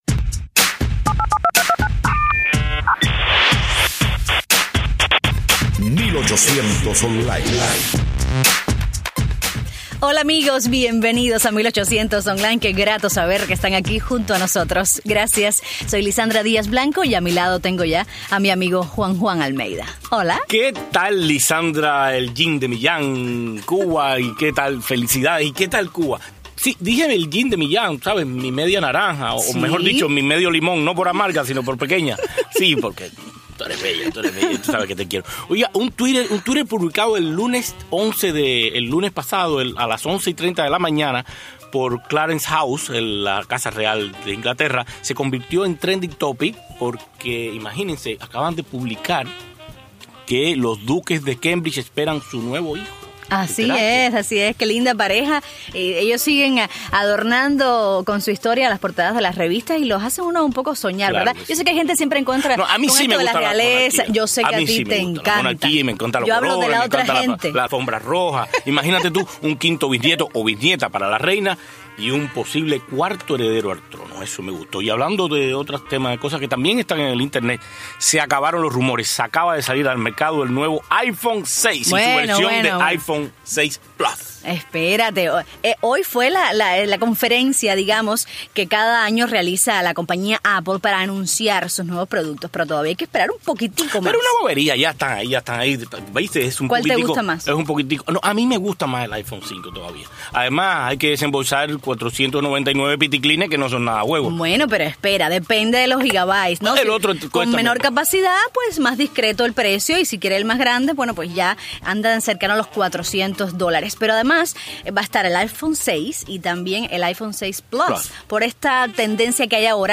En el programa en vivo nos visitaron un dúo venezolano de pop sinfónico con toques de jazz>